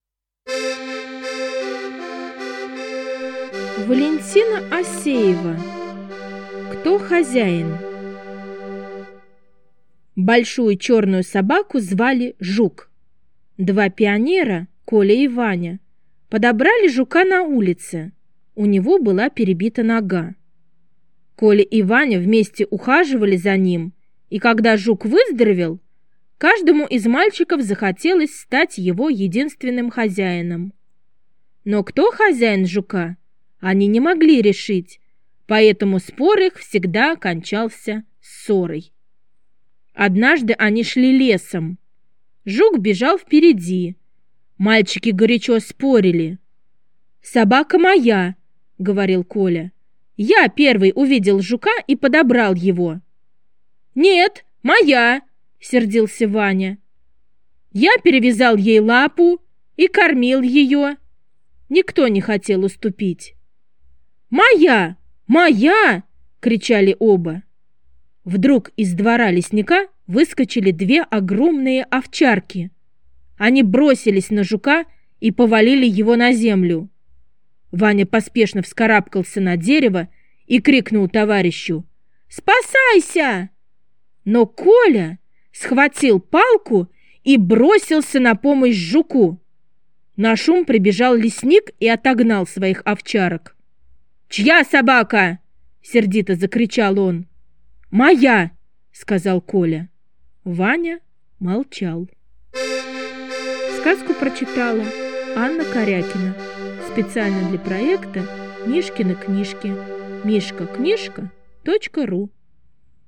Кто хозяин? - аудио рассказ Осеевой В. Два друга подобрали раненую собаку и вылечили ее, а потом никак не могли решить, чья это собака...